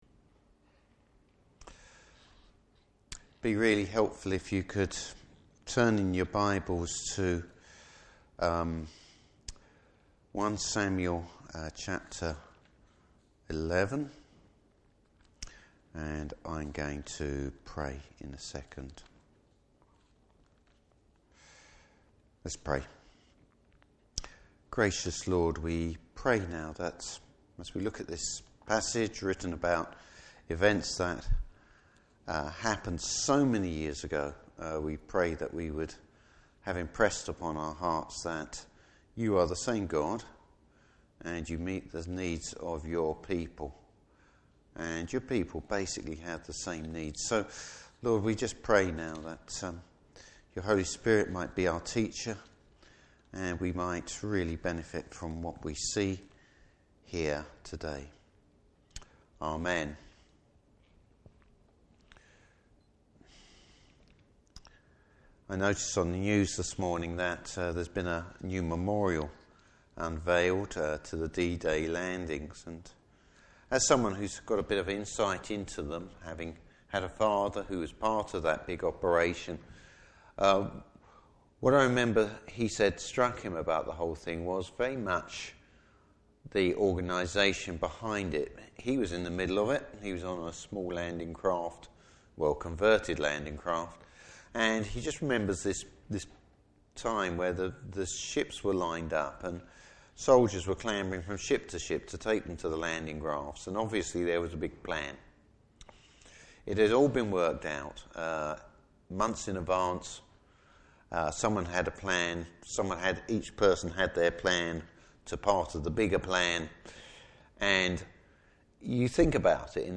Service Type: Evening Service How the Lord uses people to bring about his purposes.